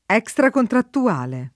vai all'elenco alfabetico delle voci ingrandisci il carattere 100% rimpicciolisci il carattere stampa invia tramite posta elettronica codividi su Facebook extracontrattuale [ H k S trakontrattu- # le ] o estracontrattuale agg.